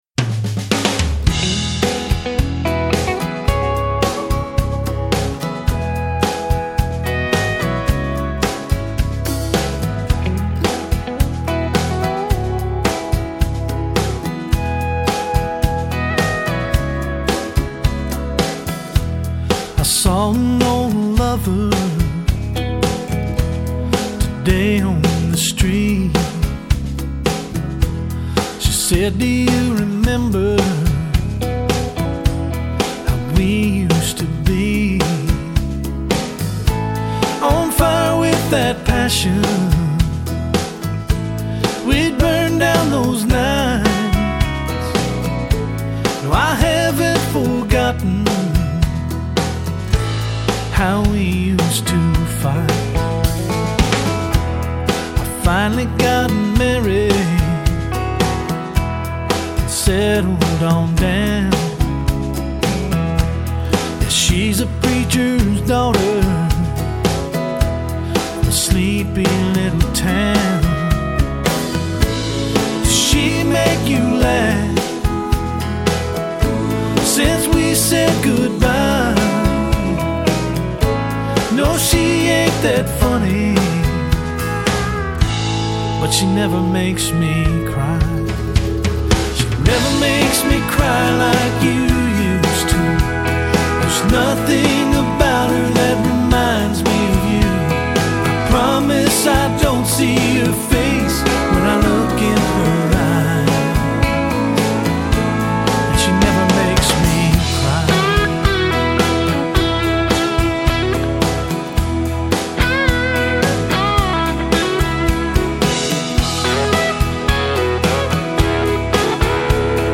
Жанр: country